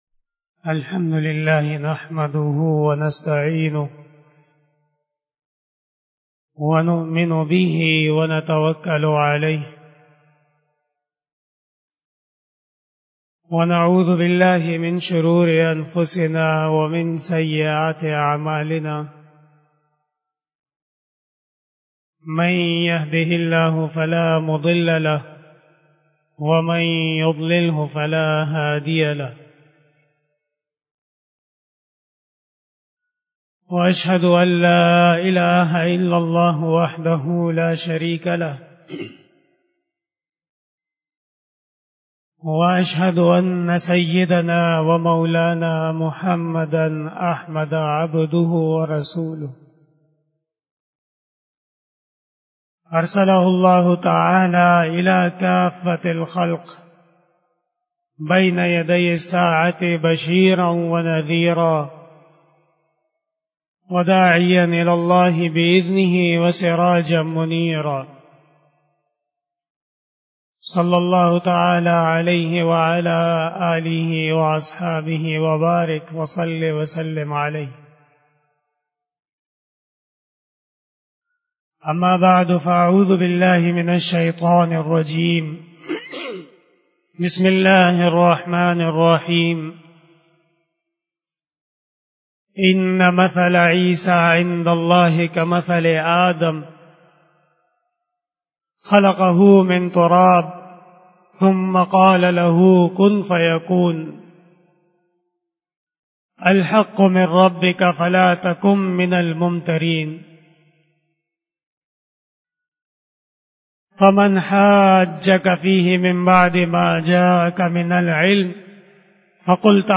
بیان جمعۃ المبارک
Khitab-e-Jummah